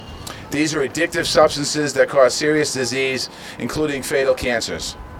WALSH AT NEWS CONFERENCE SAYING CHEWING TOBACCO PRODUCTS ARE ADDICTIVE SUBSTANCES THAT CAUSE ADDICTIVE DISEASES INCLUDING FATAL CANCERS.